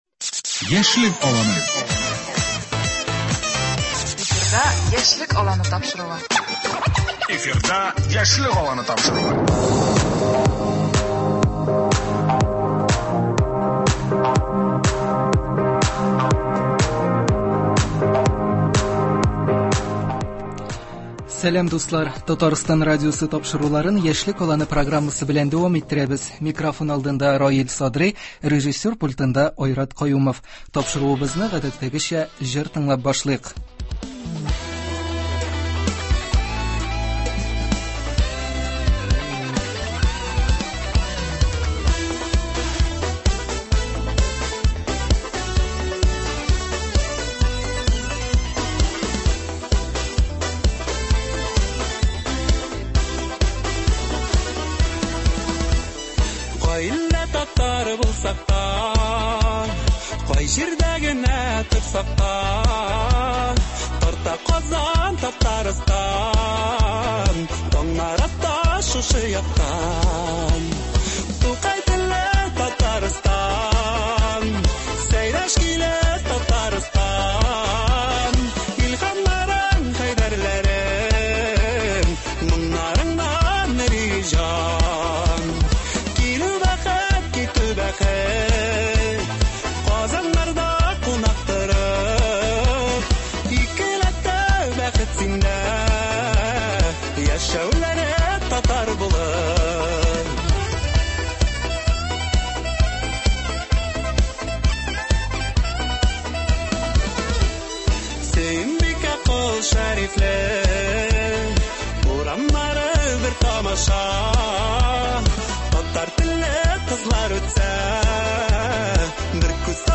Икенчедән, апрель аенда Бөтенроссия халык санын алу кампаниясе старт алачак. Бүгенге әңгәмәбездә студиябез кунагы